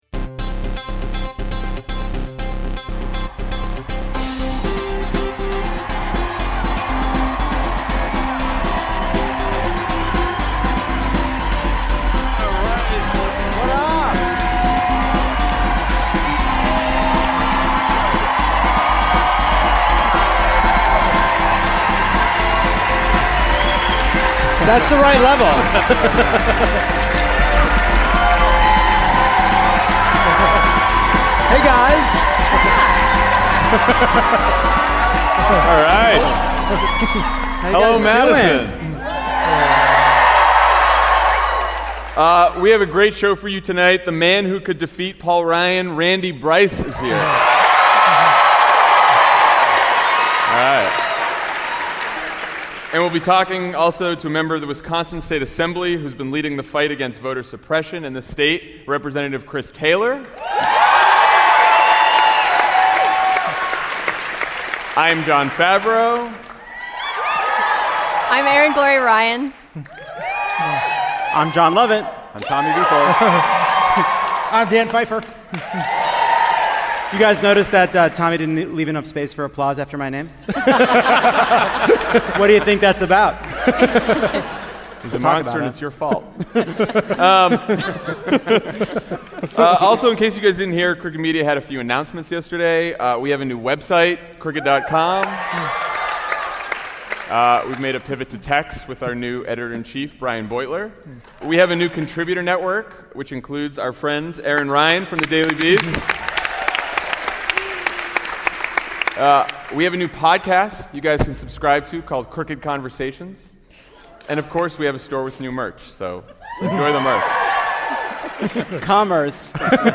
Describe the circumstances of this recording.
(LIVE from Madison)